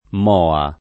moha [ m 0 a ]